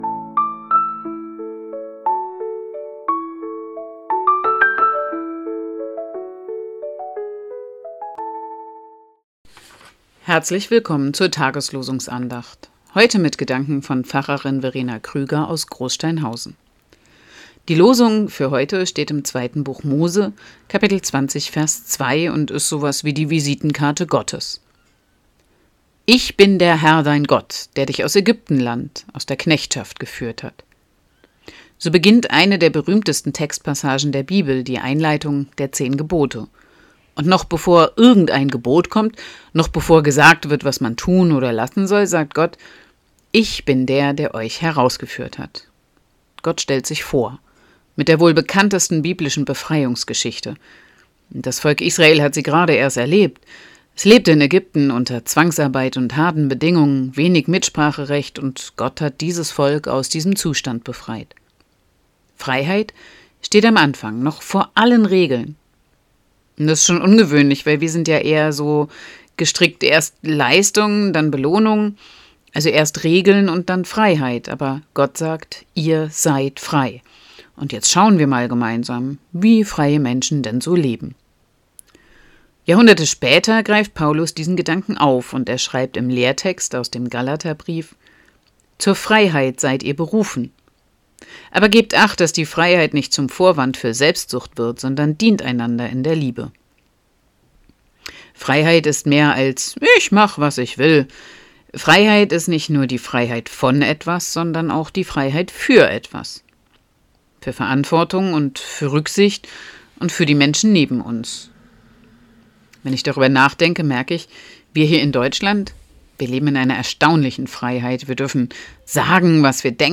Losungsandacht für Samstag, 14.03.2026